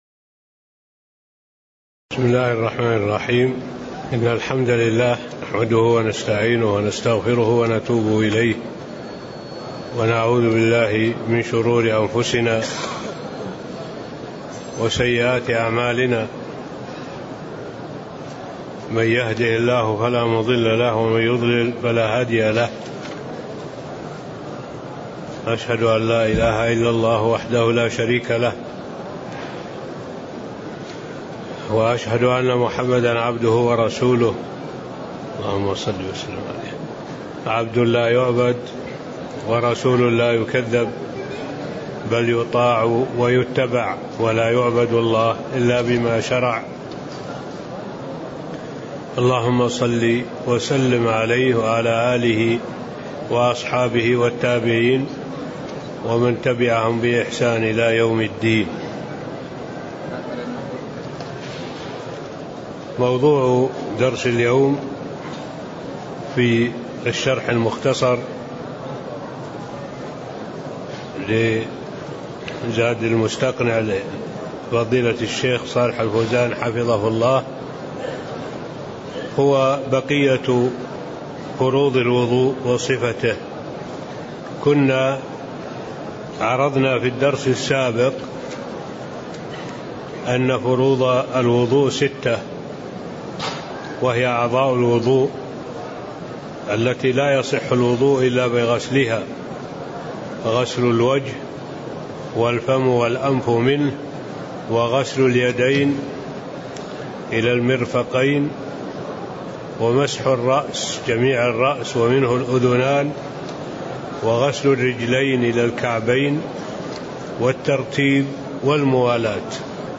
تاريخ النشر ٤ ربيع الثاني ١٤٣٤ هـ المكان: المسجد النبوي الشيخ: معالي الشيخ الدكتور صالح بن عبد الله العبود معالي الشيخ الدكتور صالح بن عبد الله العبود باب فروض الوضوء وصفته (07)